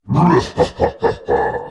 怪物笑聲